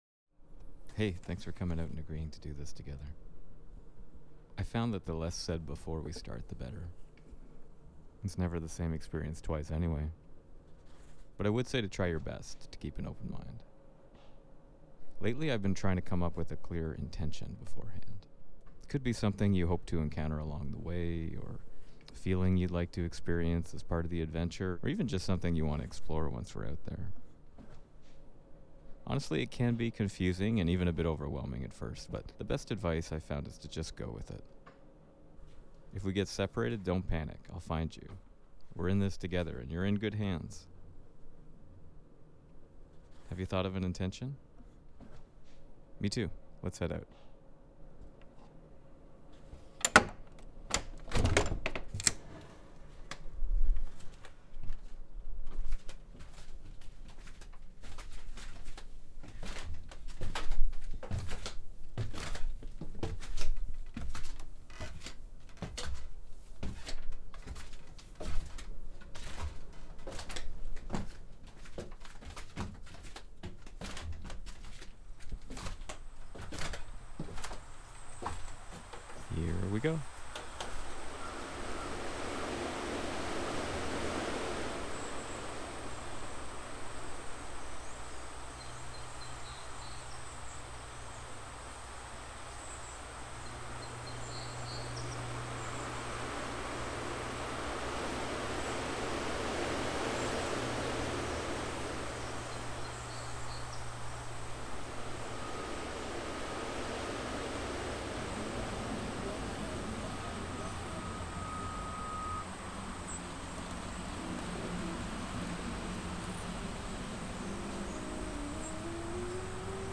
First Time invites the listener to boldly plunge “ears-first” into a guided, aural adventure through mysterious, mind-bending soundscapes where not everything is as it seems.  As extrinsic sounds increasingly infiltrate familiar sound environments, this growing sonic incongruence calls into question where certain sounds truly “belong.”
Juxtaposing sounds strongly identified with different seasons, such as boots crunching through snow, or summer insects buzzing by the coast, was an early concept.  The completed piece uses shifting seasons, alterations in time perspective and ultimately loops back in time onto itself in its final moments.
Lastly, I wished to challenge myself to use my voice and narrative to bring the listener along the adventure.